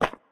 step-1.ogg.mp3